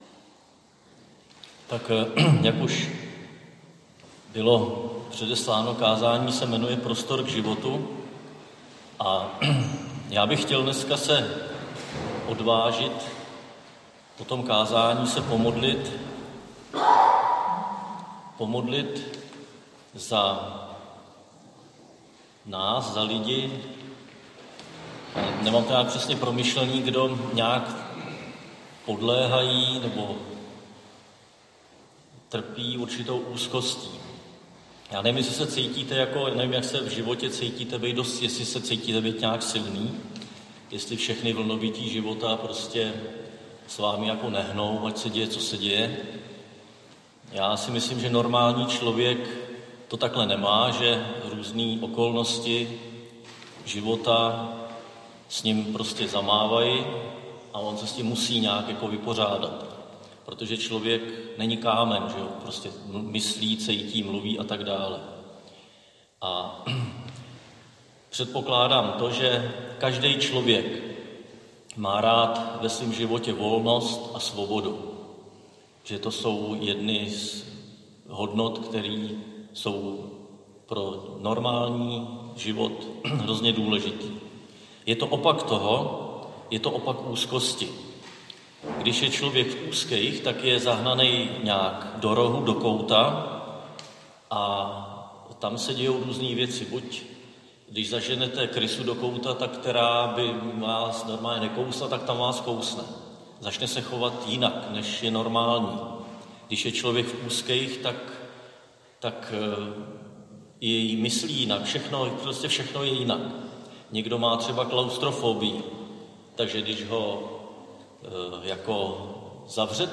Křesťanské společenství Jičín - Kázání 14.6.2020